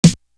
Gut Punch Snare.wav